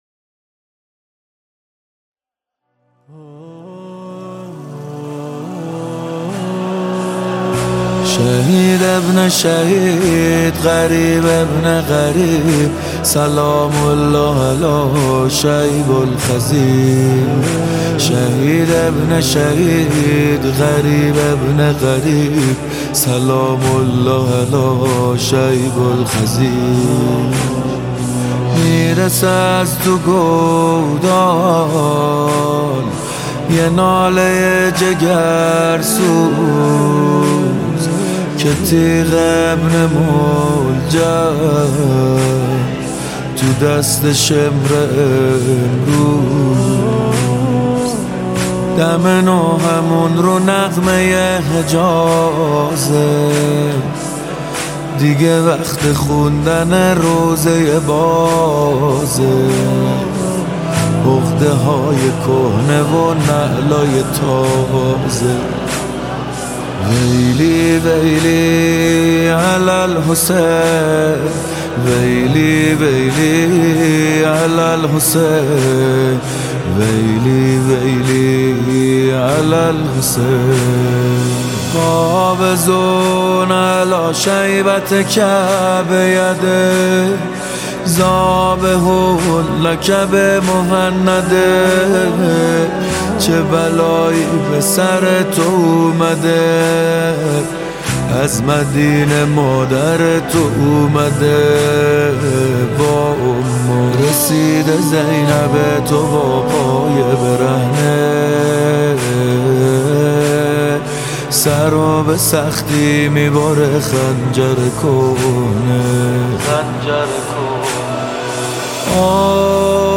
مداحی ایام سوگواری شهادت امام حسین(ع)